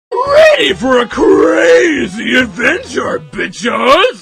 smg4-sfx-ready-for-a-crazy-adventure-blueconvert.mp3